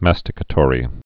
(măstĭ-kə-tôrē)